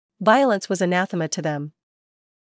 ˈvaɪələns